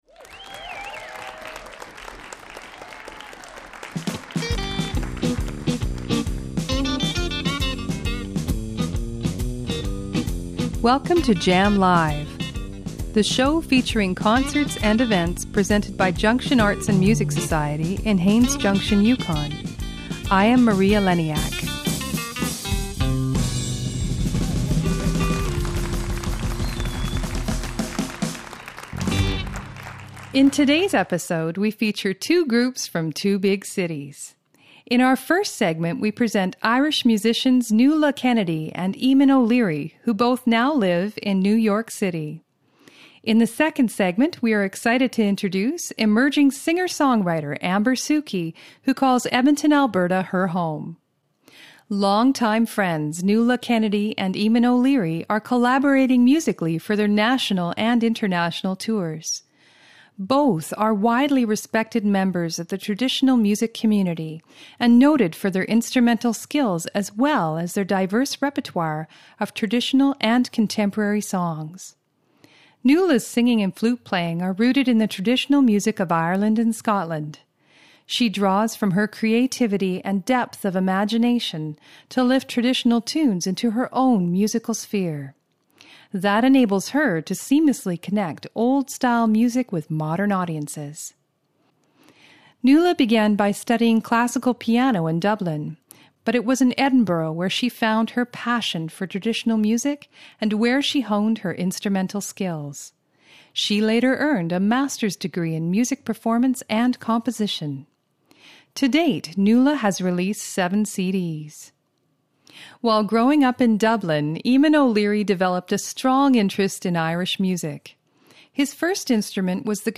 Live music recorded in Haines Junction, Yukon.